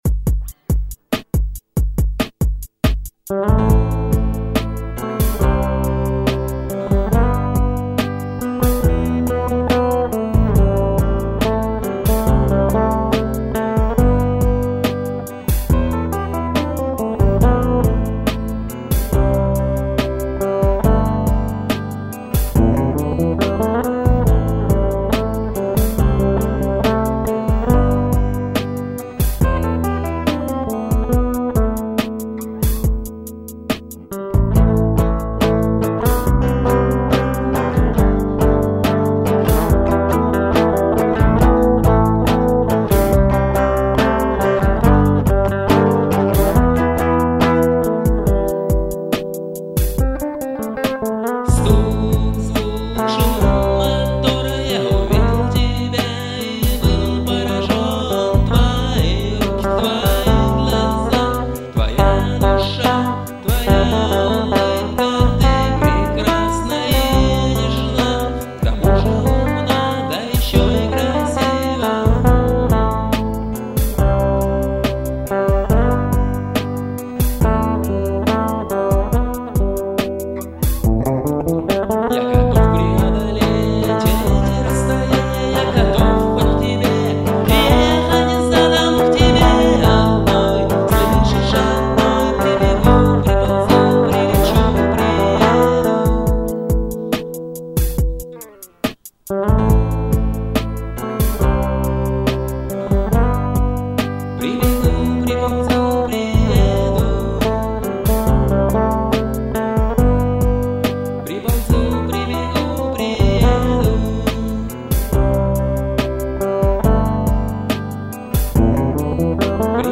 • Жанр: Легкая